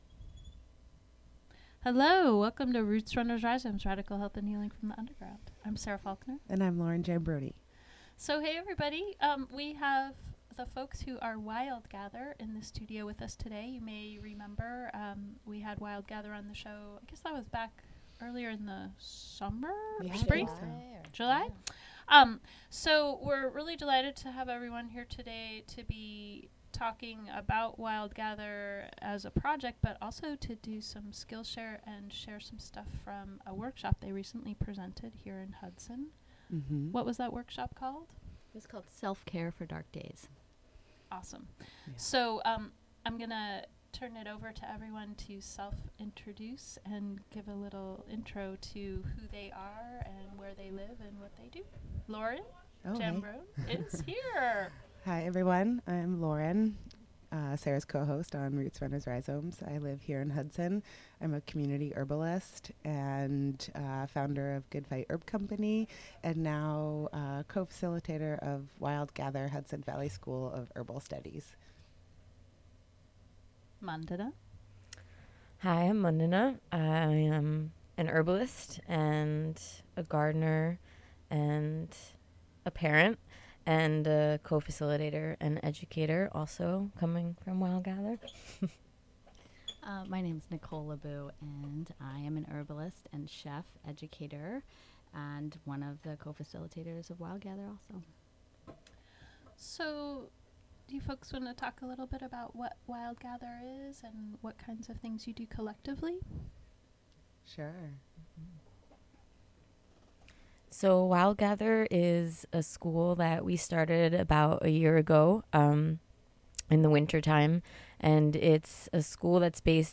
A show dedicated to exploring healing and wellness from a liberatory perspective, critiquing top-down hierarchical systems and instead looking up and around from below and alongside – the way roots and rhizomes grow. This week, facilitators from Wild Gather return for a recorded conversation about self-care and other topics.